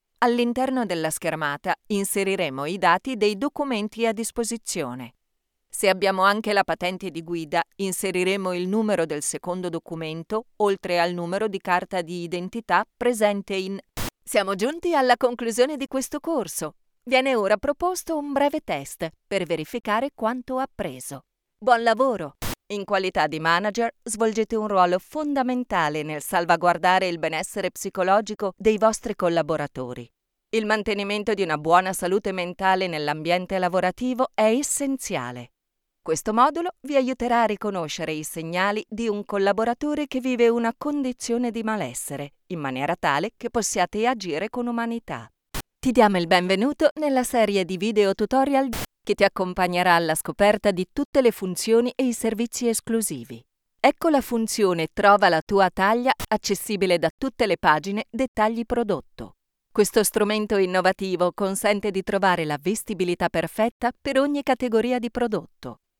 E-learning
I built a home studio with audio silent cabin where I log every day.
DeepMezzo-Soprano